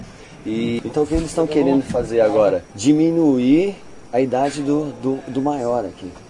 Recording at The Posada MIRANTE KonTik PraiaBranca, Sao Paulo, Brazilworried about gun possession.
Tag: 场记录 mzr50 ecm907 声音 讲话 谈话 男性